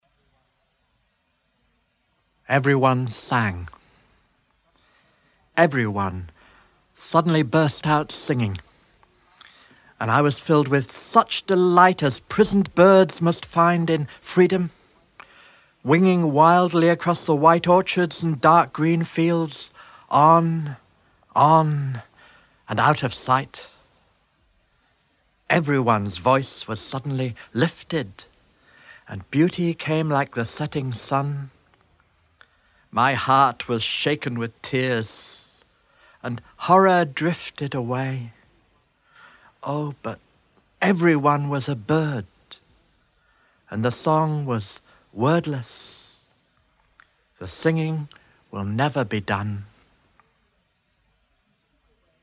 Everyone Sang by Siegfried Sassoon read by Adrian Mitchell
Siegfried-Sassoon-Everyone-Sang-read-by-Adrian-Mitchell.mp3